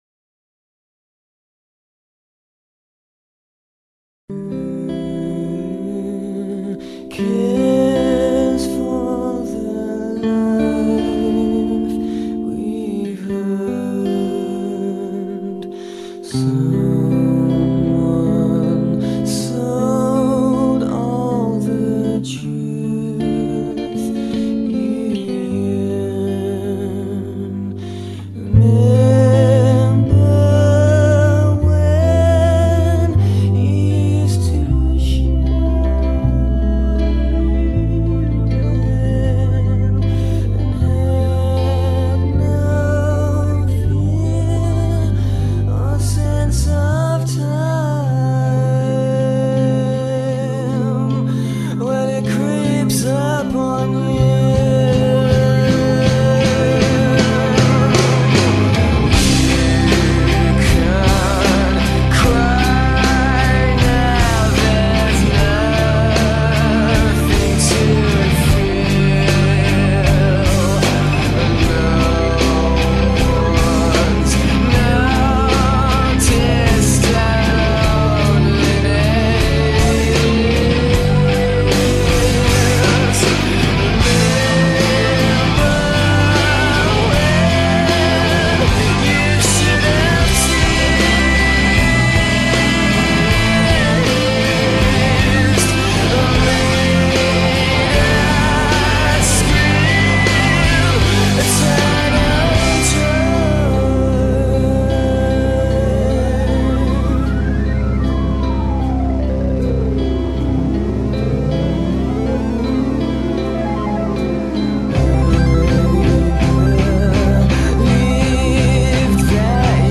drum, perkusi